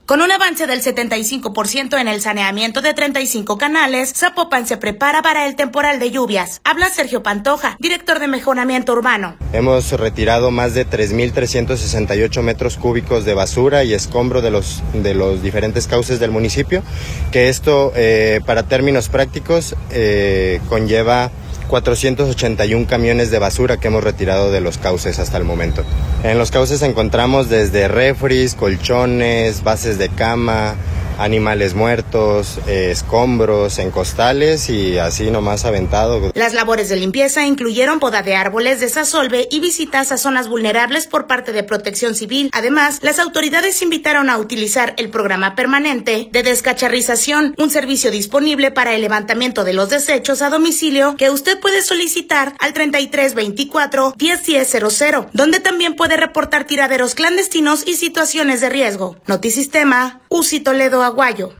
Con un avance del 75 por ciento en el saneamiento de 35 canales, Zapopan se prepara para el temporal de lluvias. Habla Sergio Pantoja, director de Mejoramiento Urbano.